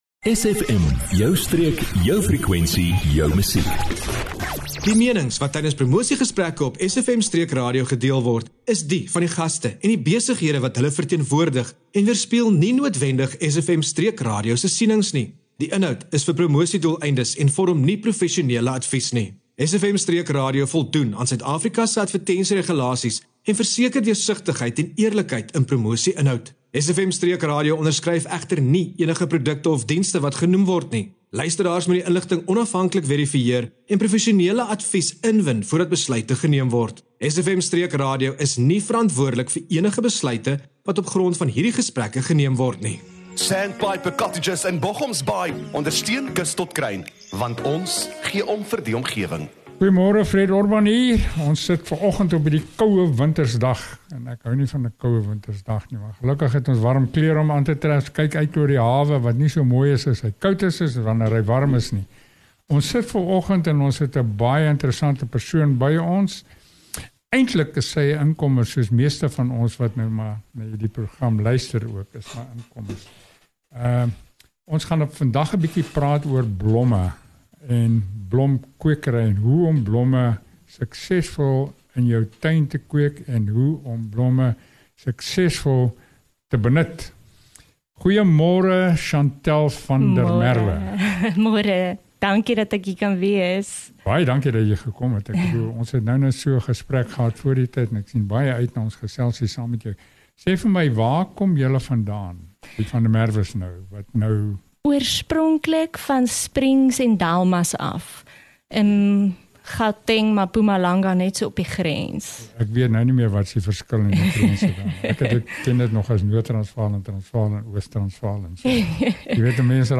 ’n inspirerende gesprek